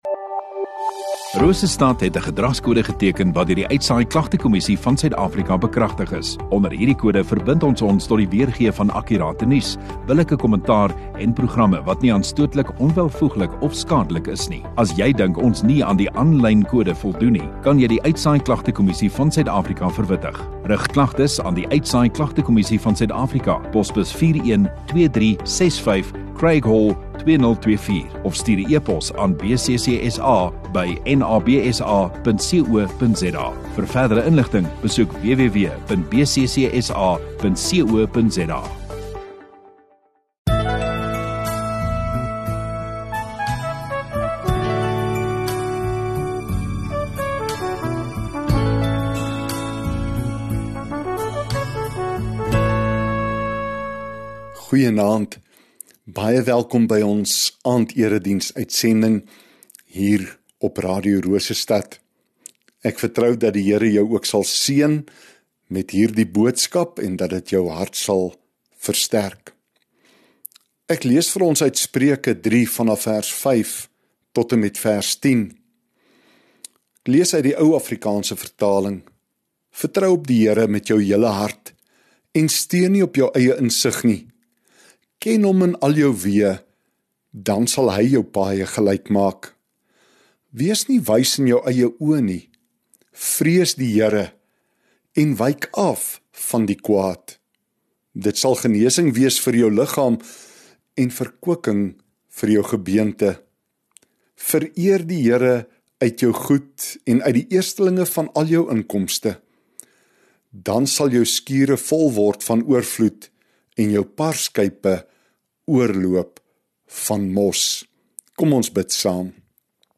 22 Jun Sondagaand Erediens